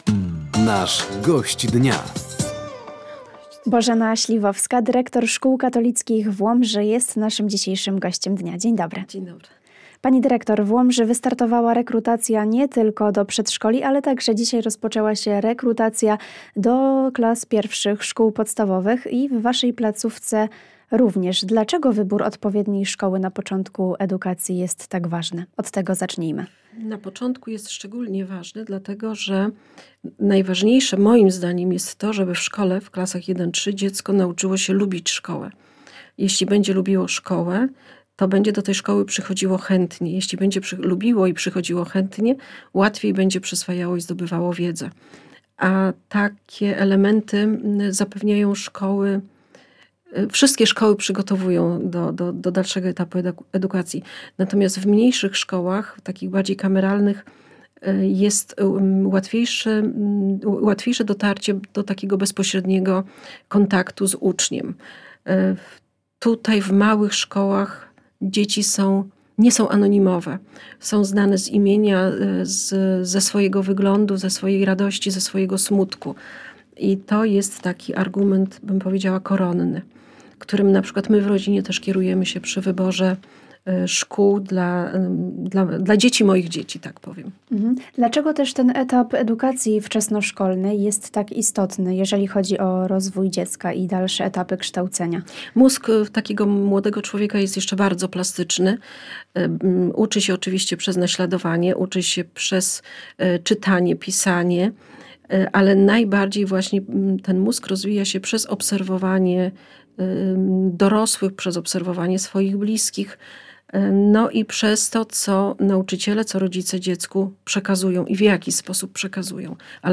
Rekrutacja do 1 klas Szkół Podstawowych, zbliżający się Dzień Otwartych Drzwi w Szkołach Katolickich w Łomży oraz Narodowy Dzień Pamięci Żołnierzy Wyklętych – to główne tematy rozmowy podczas audycji ,,Gość Dnia”.